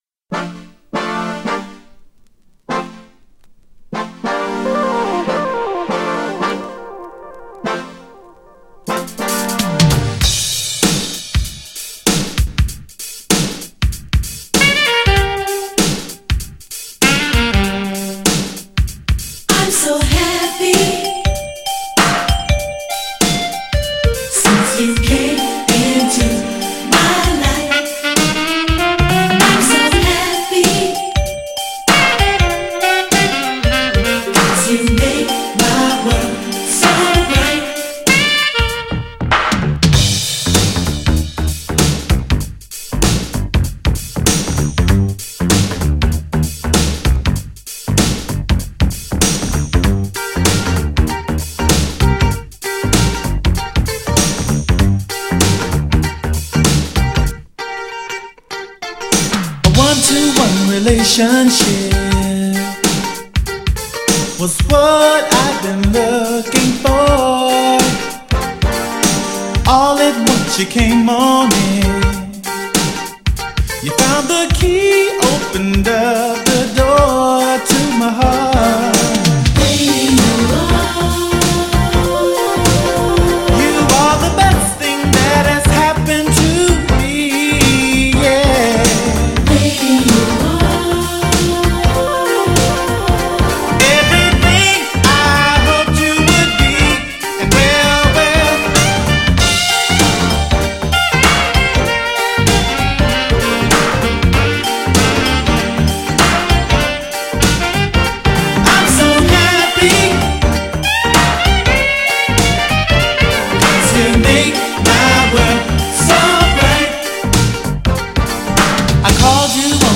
GENRE R&B
BPM 101〜105BPM
# FUSION # GROOVY # INSTRUMENTAL # JAZZY